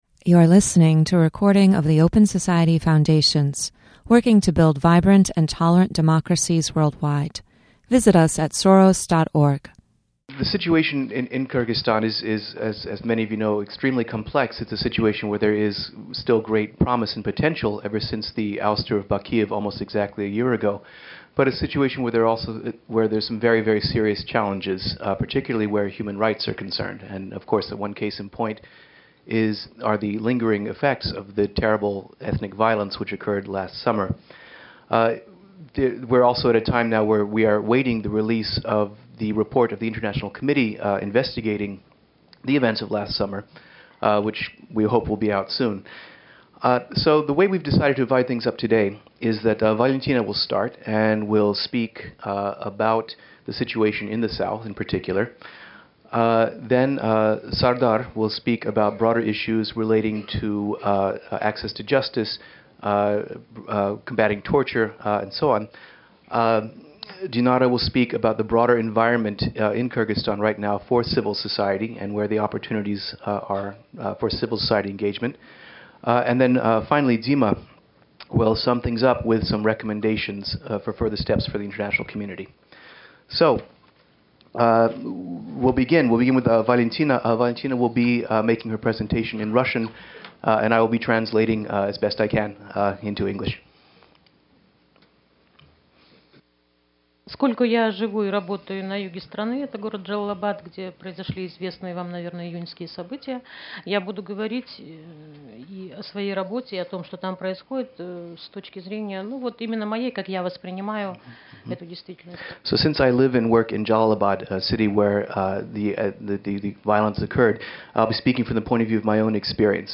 Near the one-year anniversary of the second Kyrgyz revolution and the formal presentation of the report of the International Independent Commission (the "Kiljunen Commission") on the events in the South, the Open Society Foundations hosted a roundtable discussion with two of Kyrgyzstan’s best-known human rights activists.